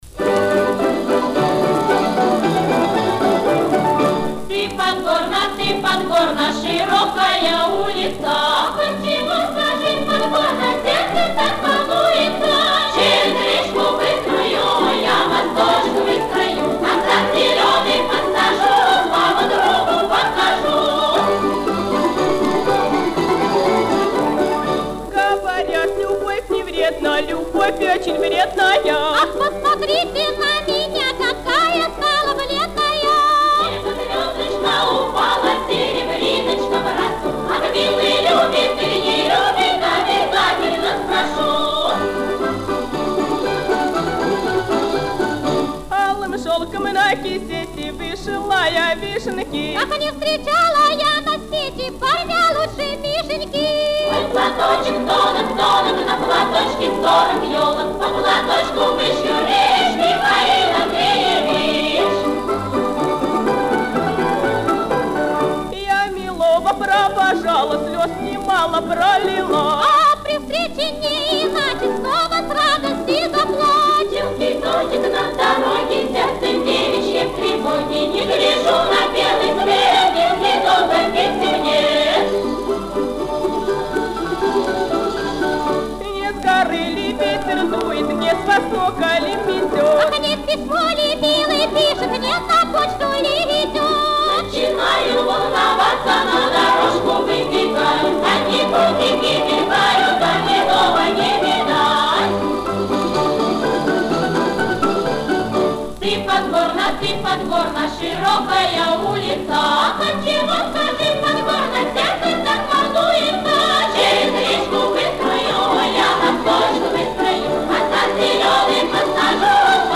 Частушки.